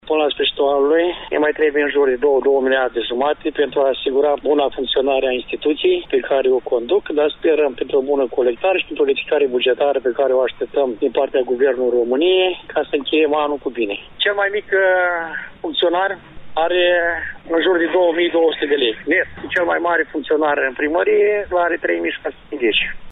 La Primăria Drânceni lucrează 28 de funcţionari. Primarul Gelu Pecheanu recunoaşte că pentru ultimele două luni din an încă nu are asiguraţi banii de salarii.